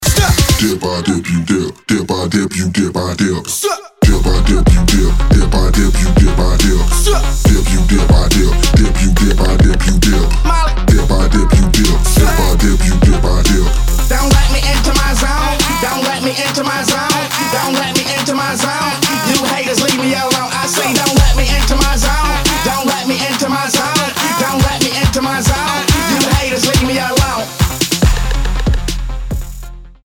• Качество: 320, Stereo
мужской голос
Alternative Hip-hop